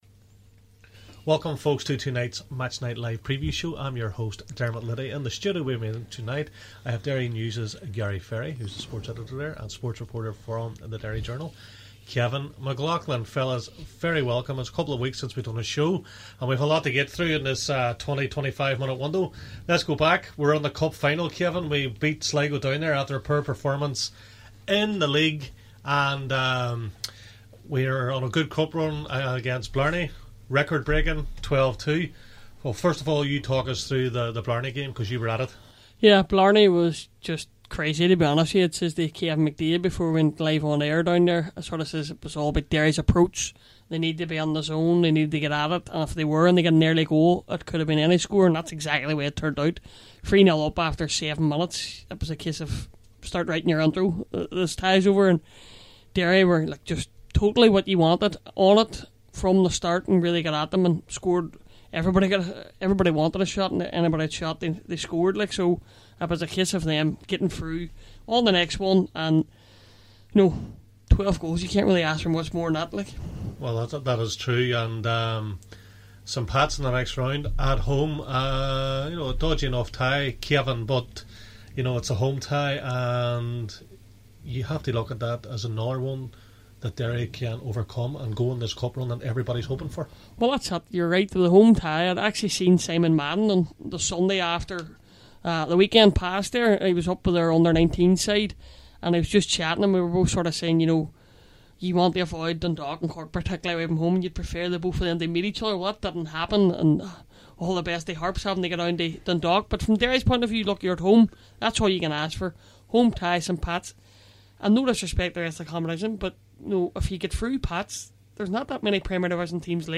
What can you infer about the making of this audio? This show will also be going out on 105.3fm just after 7pm Friday night.